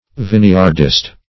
Vineyardist \Vine"yard*ist\, n. One who cultivates a vineyard.